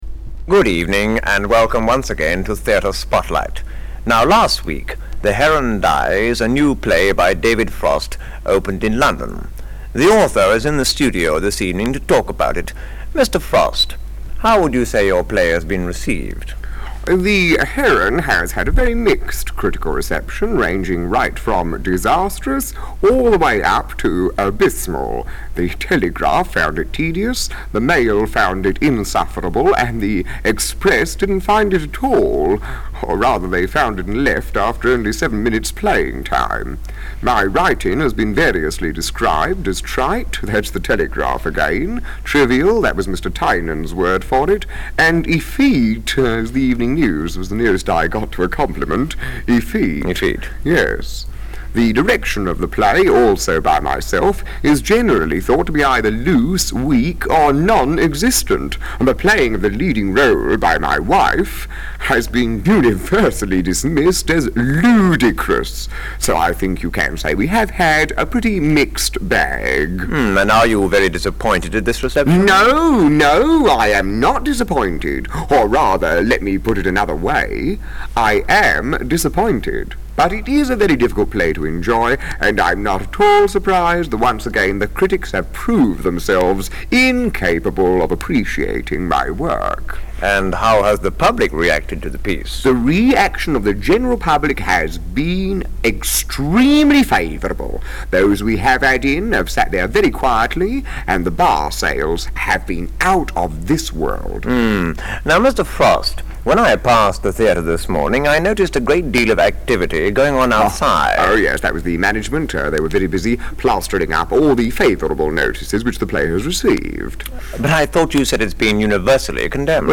A: Interviewer, B: Interviewee: “David Frost” (Not the real David Frost, of course, but Kenneth Williams.)